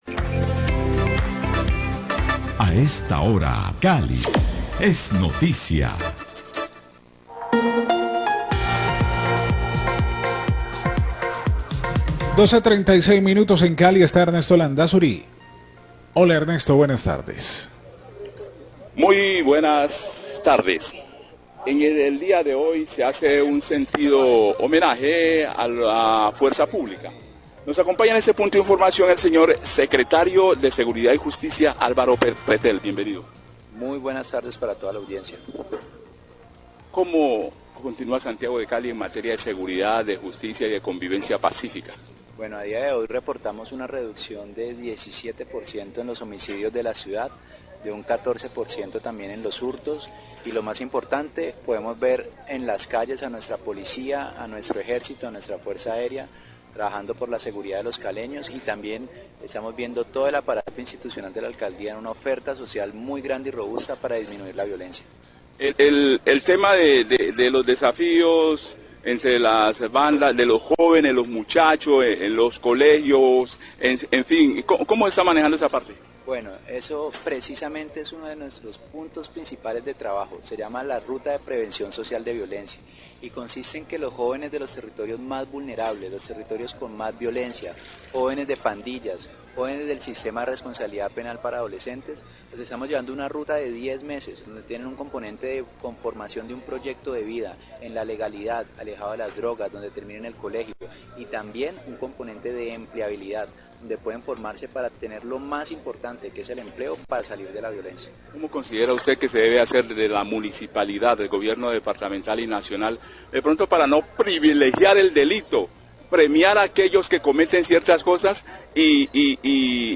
Radio
entrevista